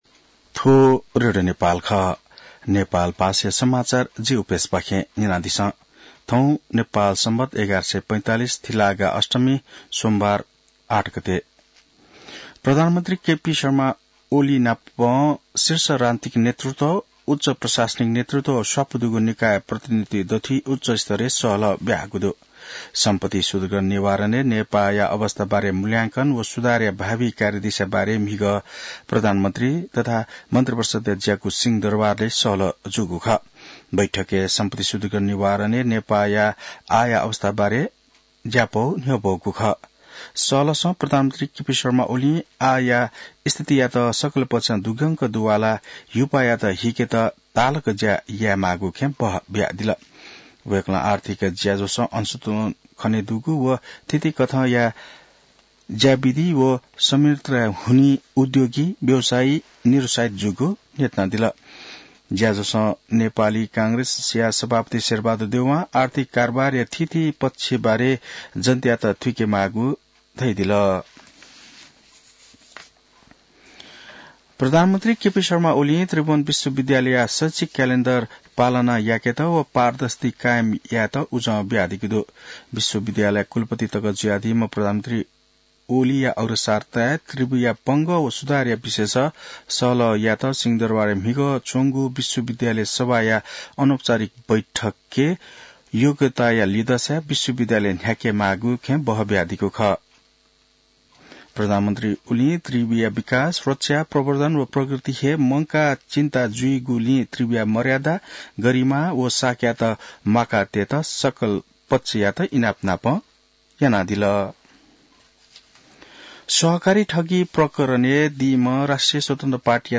नेपाल भाषामा समाचार : ९ पुष , २०८१